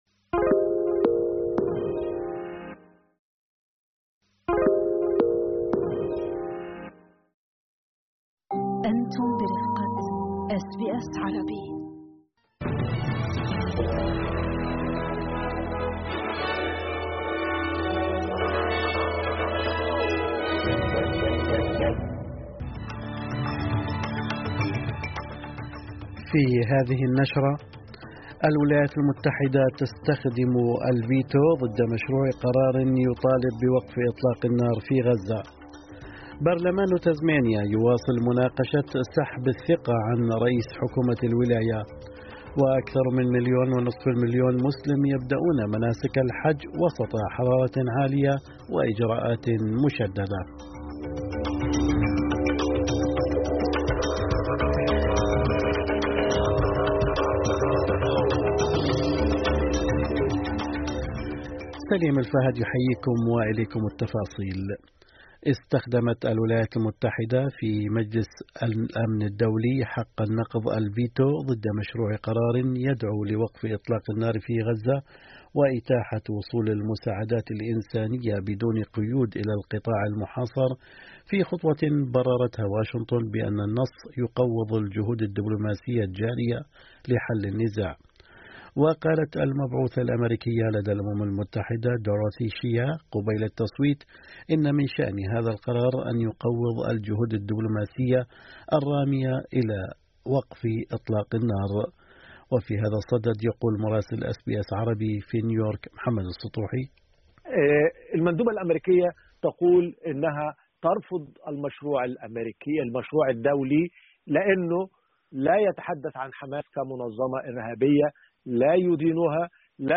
نشرة أخبار الصباح 5/6/2025